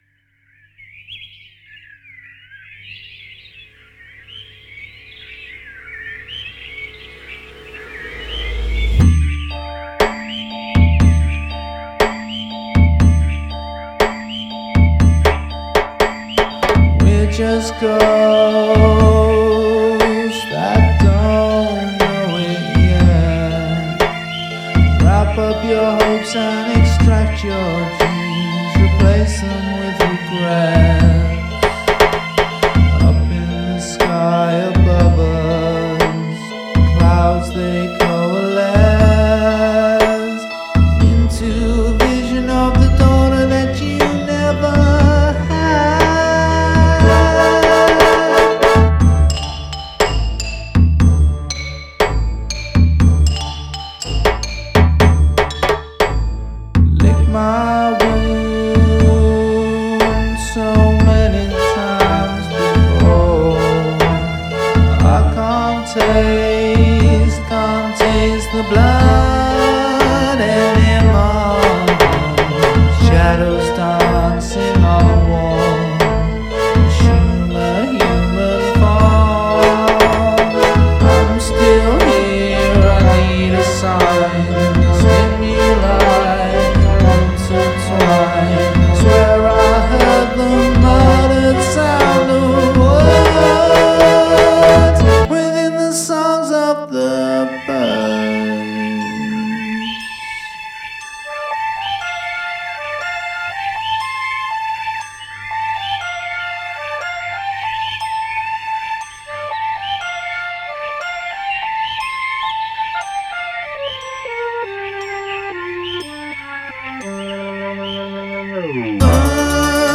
Use of field recording